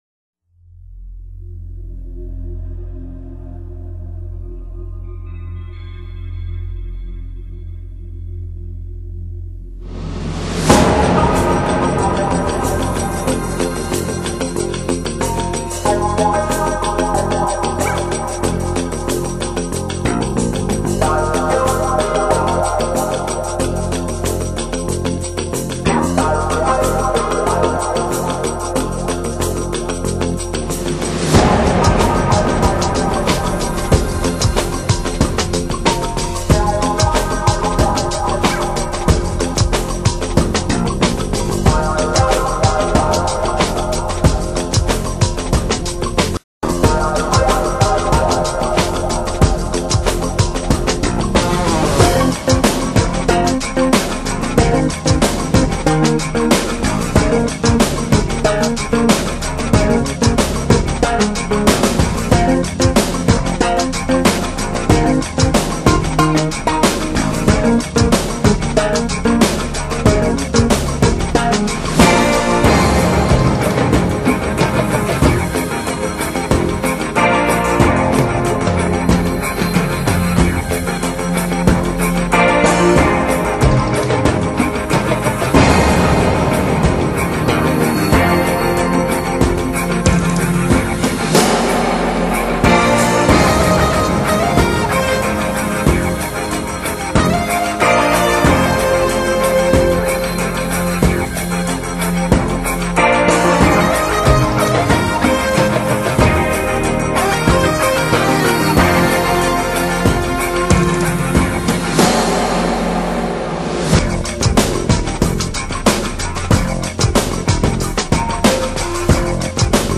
音乐类型: Newage
丛林里的鸟虫轻声吟唱，男人们欢快的和声，勿远勿近。
节奏鲜明的电子音乐，随着男人们矫健的步履，进入丛林的深处。